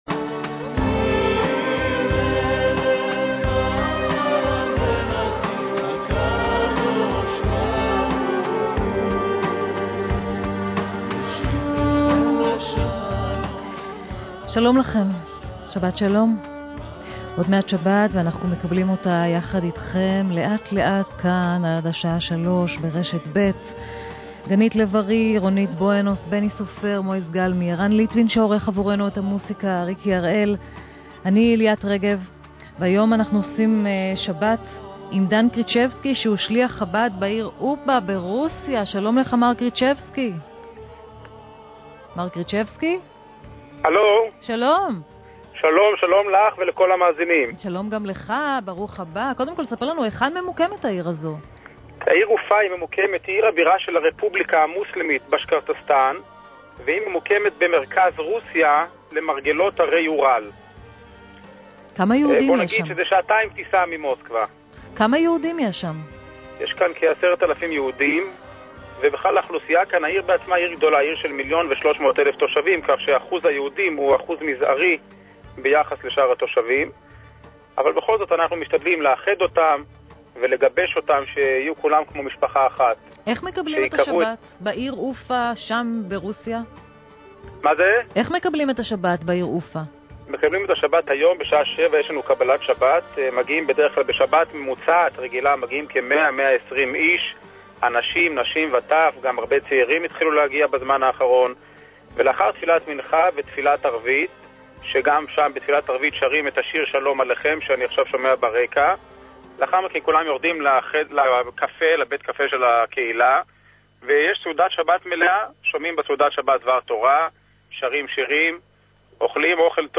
בתכניתה של ליאת רגב, ברשת ב' של 'קול ישראל', ביום שישי אחר-הצהריים, עושים ביקור בכל שבוע בקהילה יהודית אחרת בעולם.